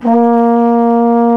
TENORHRN A#1.wav